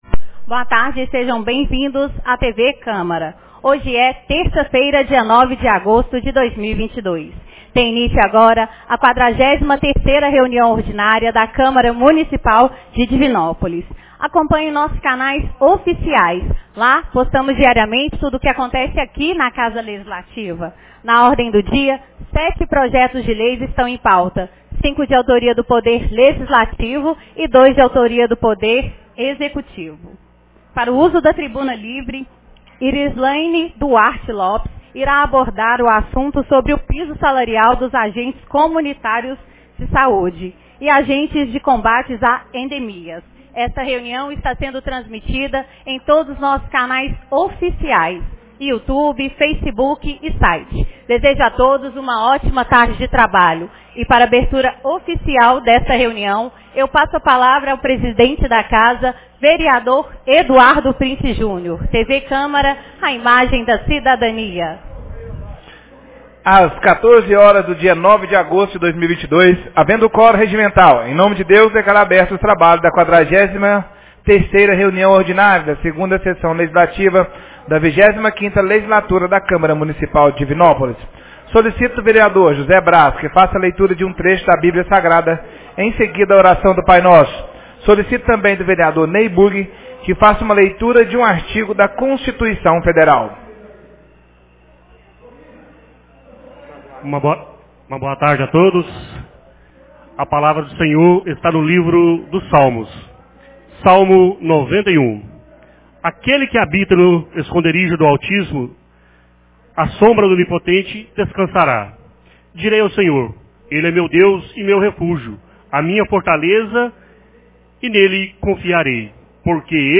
43ª Reunião Ordinária 09 de agosto de 2022